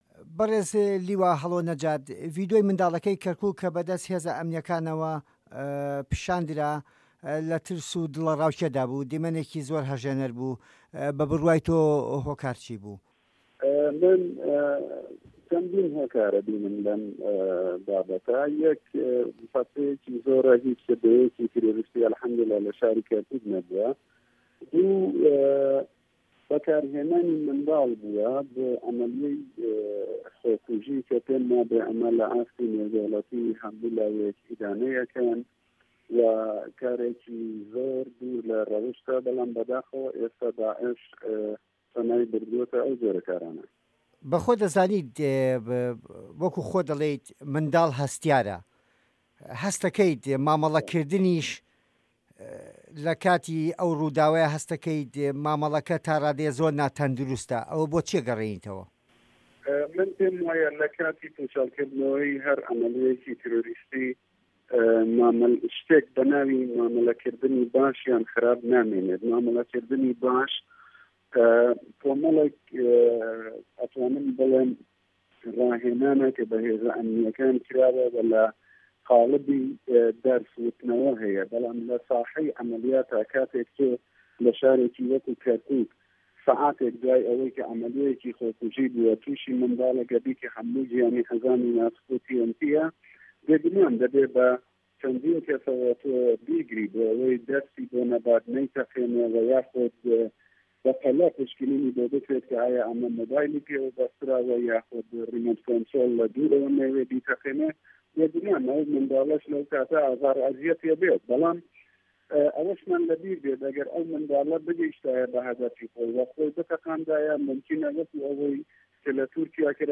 وتووێژ لەگەڵ لیوا هەڵۆ نەجات